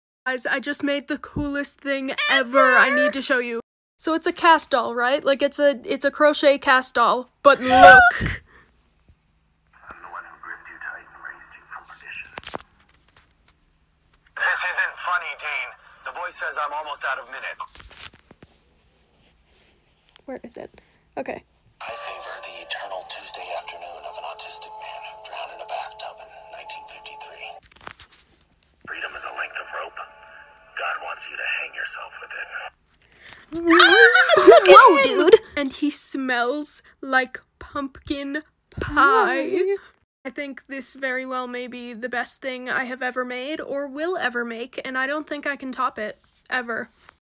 sound effects inspired by queen